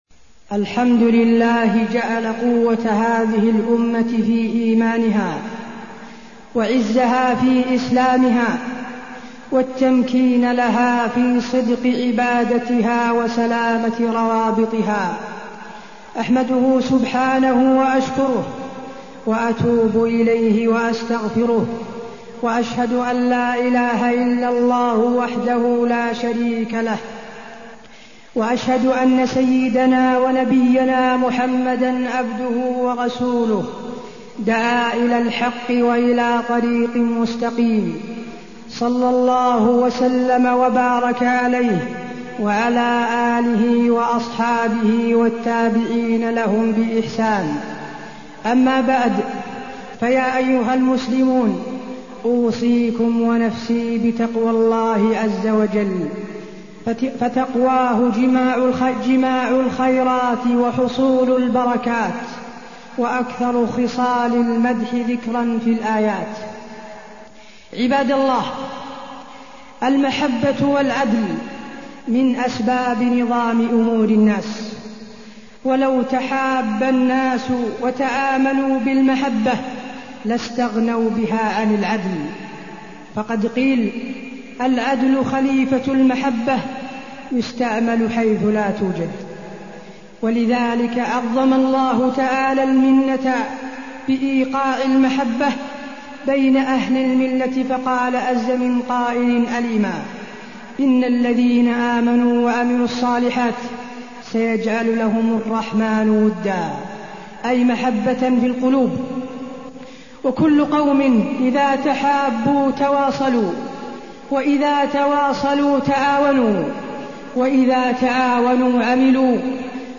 تاريخ النشر ١٦ جمادى الأولى ١٤٢٠ هـ المكان: المسجد النبوي الشيخ: فضيلة الشيخ د. حسين بن عبدالعزيز آل الشيخ فضيلة الشيخ د. حسين بن عبدالعزيز آل الشيخ الحب والبغض في الله